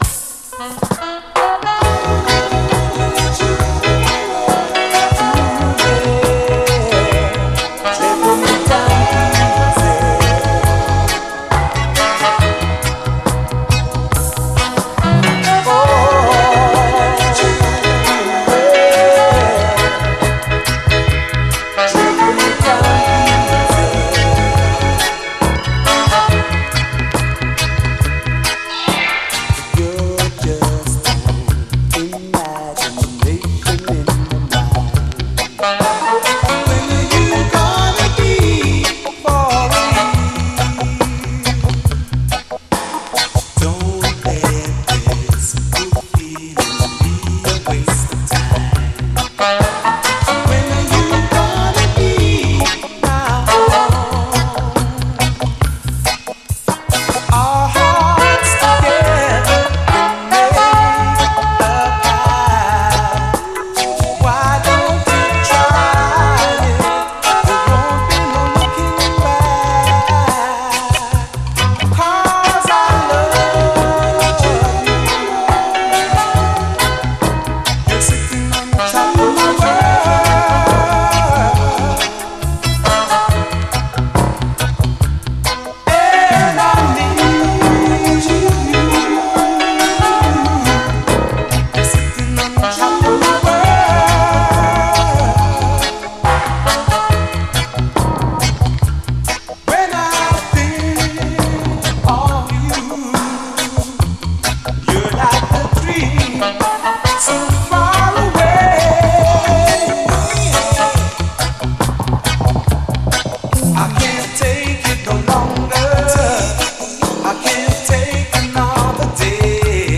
REGGAE
キラー・マイナーUKラヴァーズ！超絶ドリーミーなサウンドにKOされる桃源郷ラヴァーズ！両面最高。
試聴ファイルはこの盤からの録音です
美しいハーモニーとダビーなトラックが極上！
両面後半はダブ。